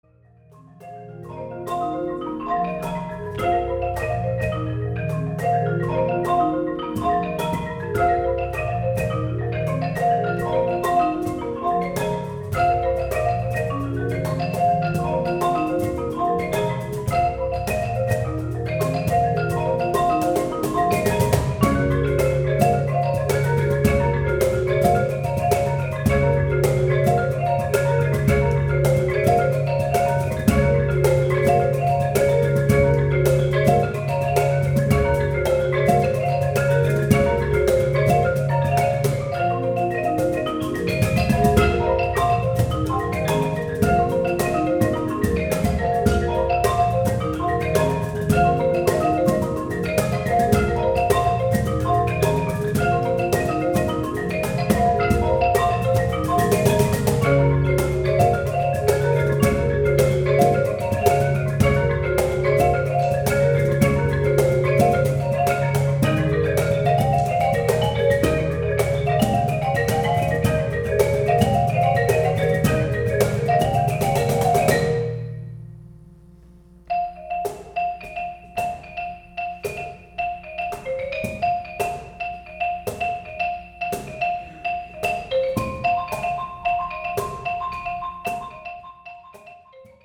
Challenging marimba quartet - ca. 6' 03" in duration.